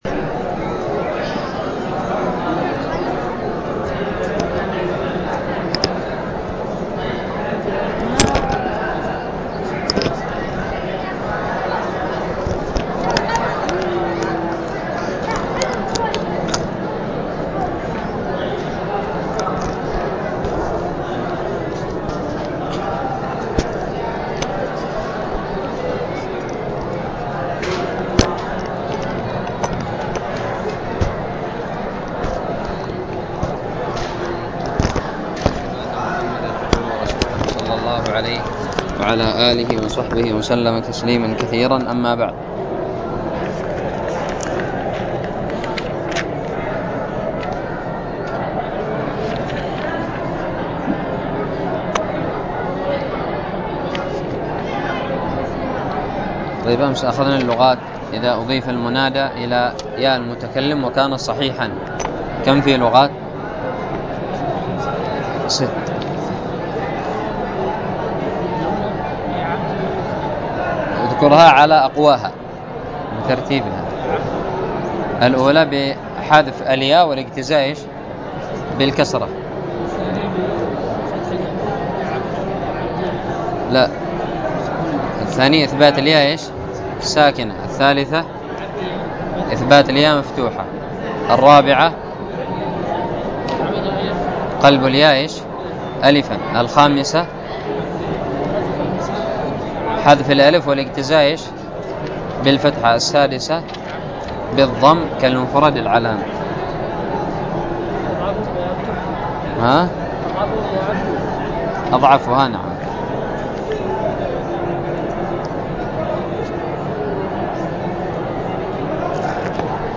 ألقيت بدار الحديث بدماج